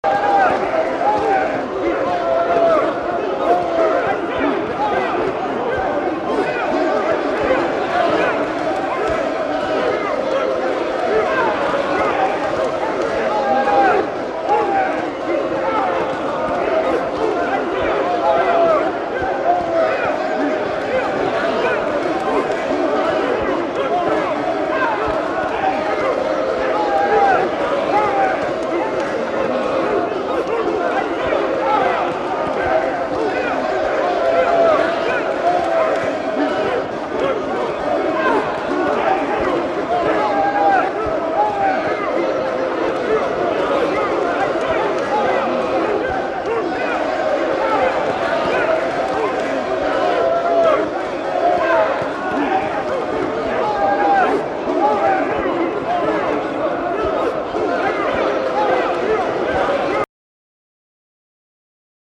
The SoundScape at Caerphilly Castle is installed along one of the battlements. Activated via PIR as visitors walk into the area 4 speakers come to life with the sounds of battle all along the defences.